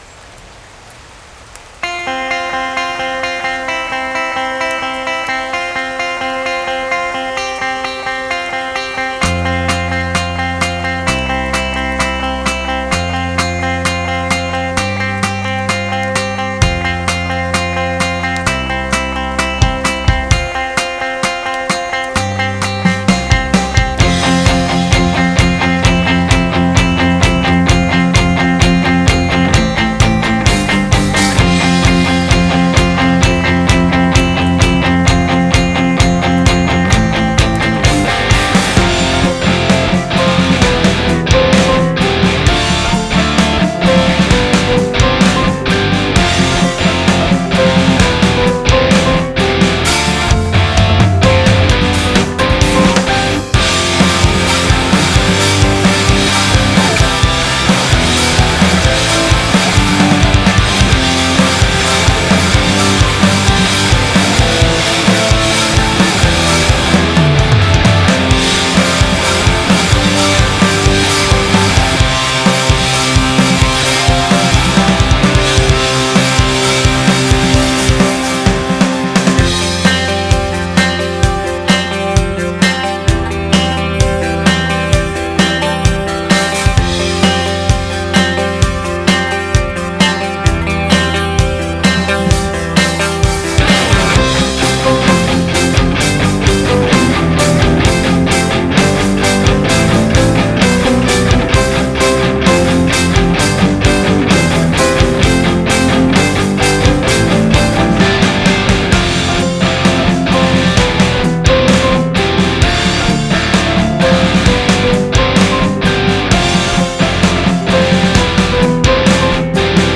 ■OFF VOCAL